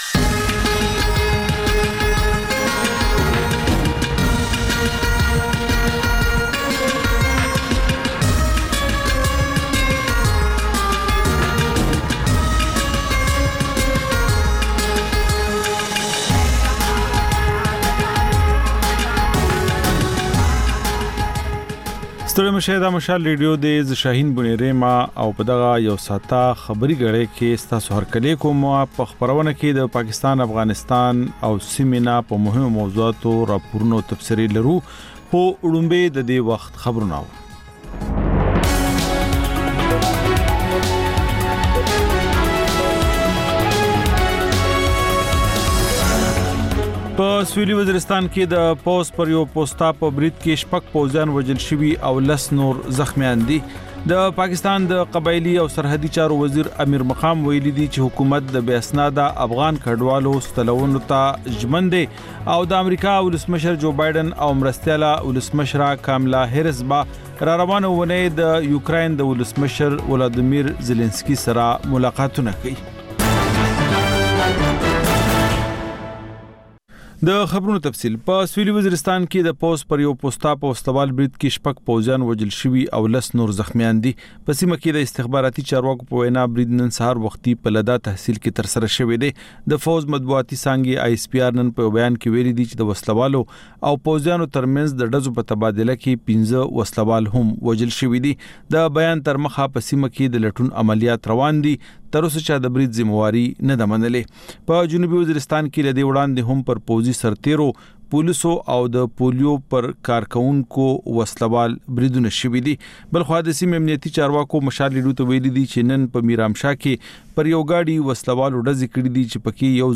دلته د مشال راډیو د ۱۴ ساعته خپرونو دویمه او وروستۍ خبري ګړۍ تکرار اورئ. په دې خپرونه کې تر خبرونو وروسته بېلا بېل سیمه ییز او نړیوال رپورټونه، شننې، مرکې، کلتوري او ټولنیز رپورټونه خپرېږي.